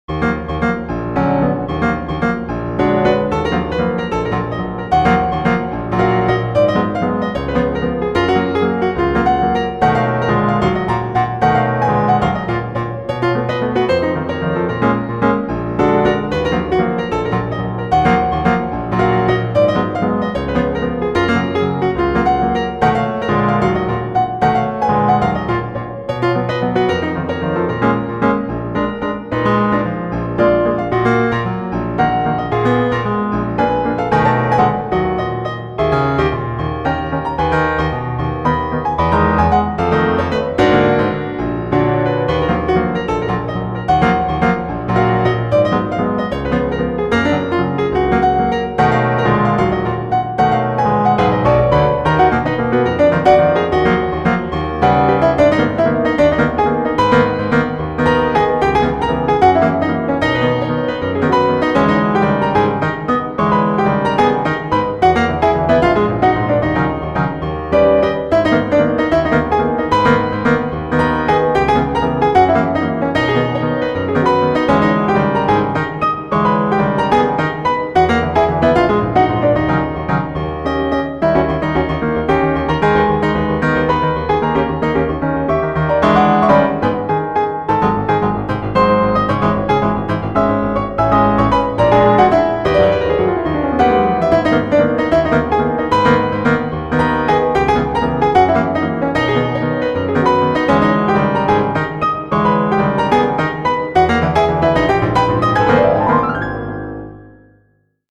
the jazz waltz rendering.